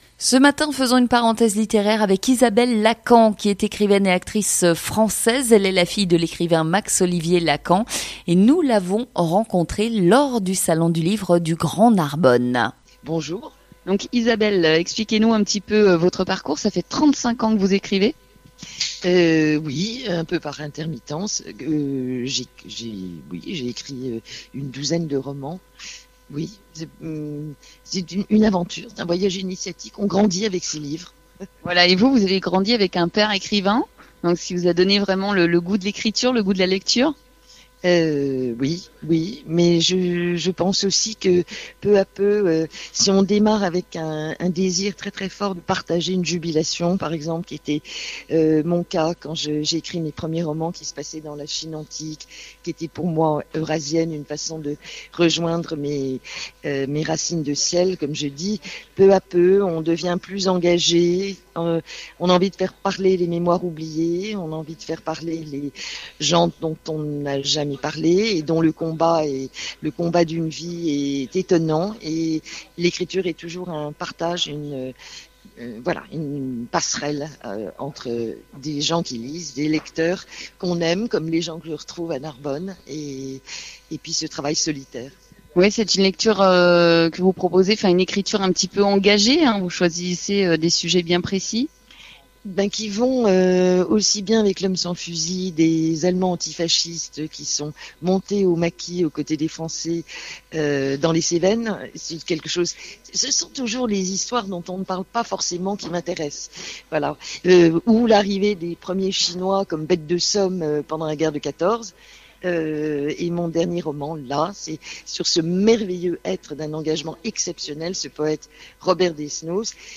Dimanche 6 mai 2018 de 11h à 12h, GRAND SUD FM en direct du cours Mirabeau à Narbonne avec Ysabelle LACAMP, écrivaine & actrice française au salon du livre du Grand Narbonne